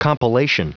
Prononciation du mot compilation en anglais (fichier audio)
Prononciation du mot : compilation